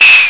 And when you noticed the sound, you will find that there’s something tiny difference, I guess it’s the recording distortion.